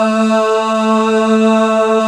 Index of /90_sSampleCDs/Club-50 - Foundations Roland/VOX_xMaleOoz&Ahz/VOX_xMale Ahz 1M
VOX XBH AH04.wav